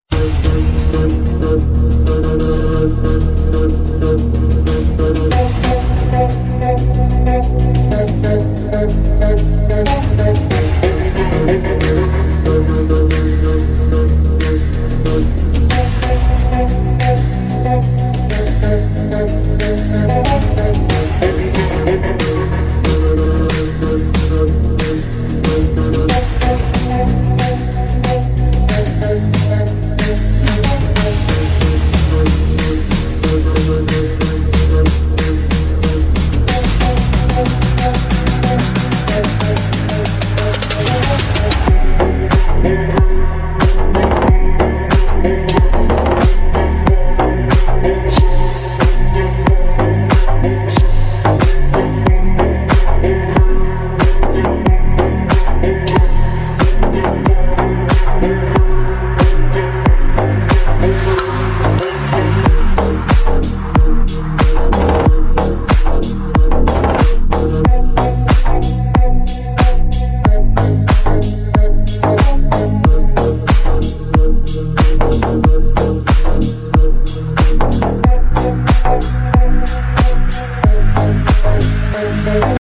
压迫感背景音乐BGM